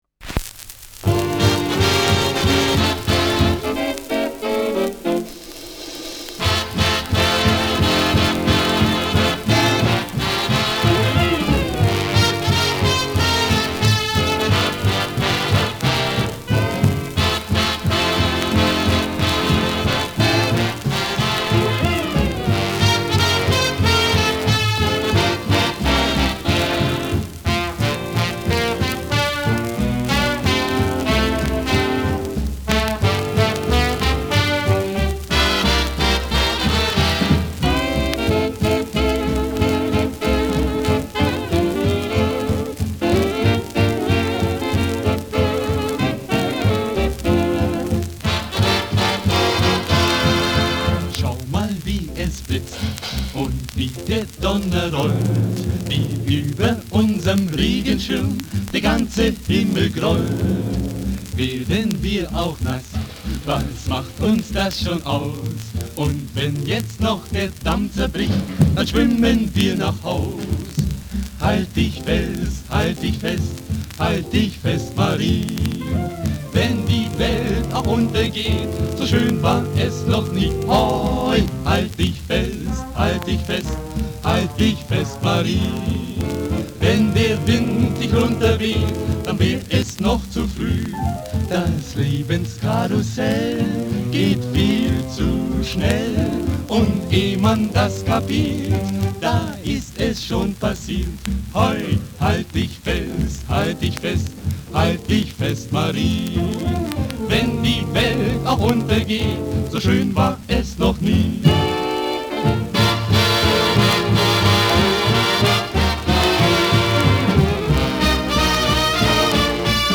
Schellackplatte
Vereinzelt leichtes Knacken